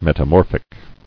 [met·a·mor·phic]